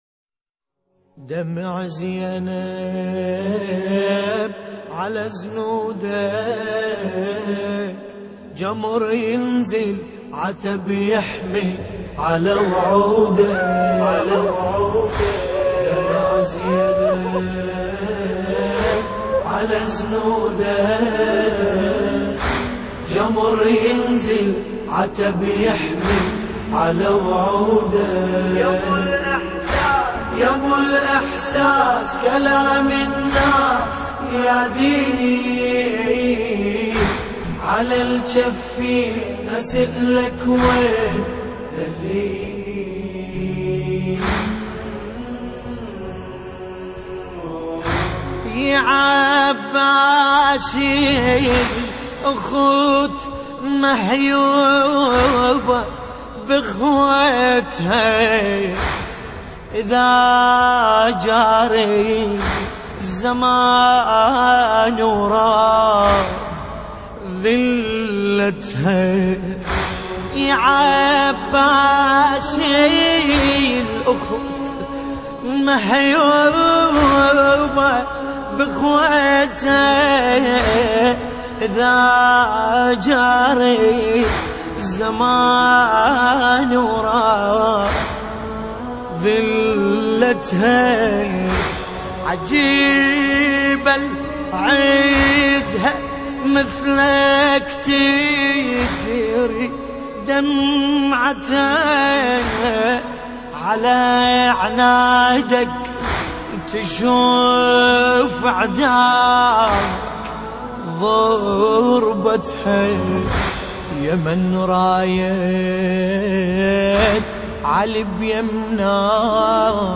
خیلی سوزناکه ...